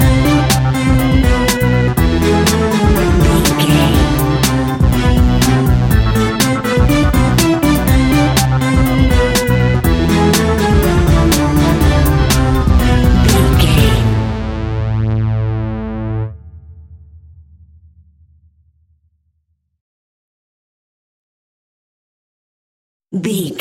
Aeolian/Minor
ominous
eerie
groovy
funky
electric organ
synthesiser
drums
strings
percussion
spooky
horror music